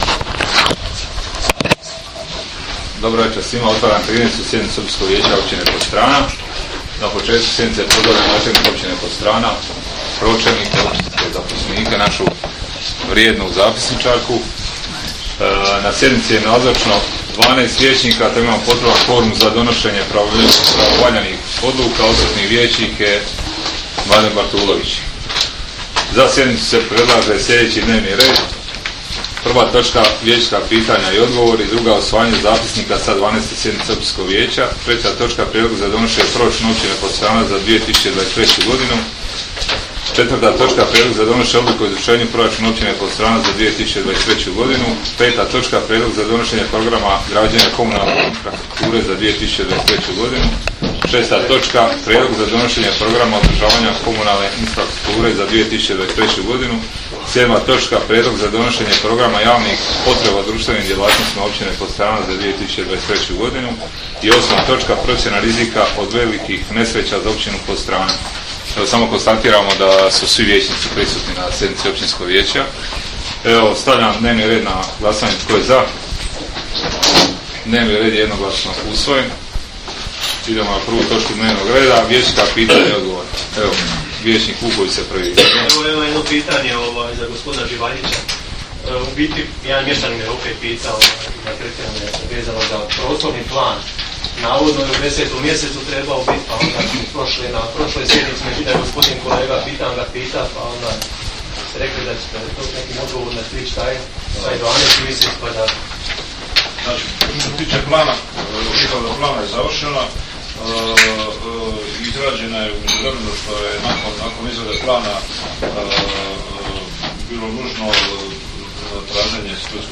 13. SJEDNICU OPĆINSKOG VIJEĆA OPĆINE PODSTRANA
Sjednica će se održati dana 15. prosinca (četvrtak) 2022. godine u 19,00 sati u vijećnici Općine Podstrana.